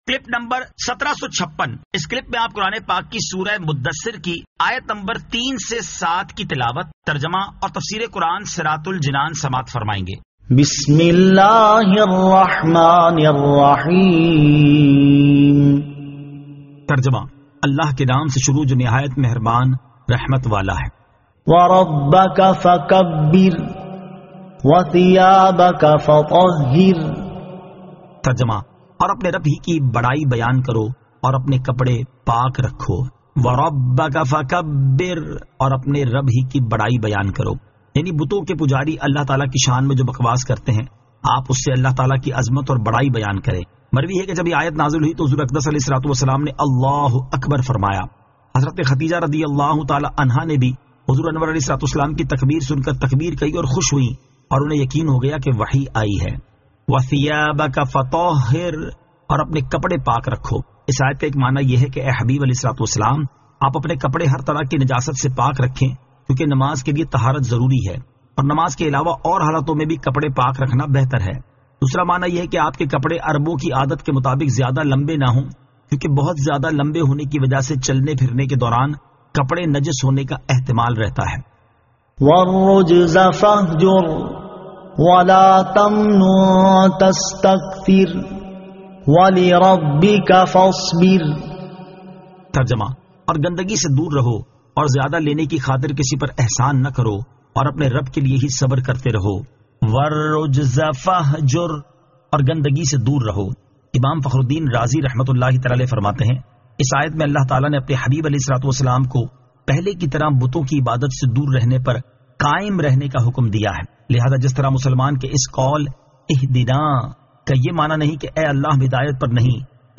Surah Al-Muddaththir 03 To 07 Tilawat , Tarjama , Tafseer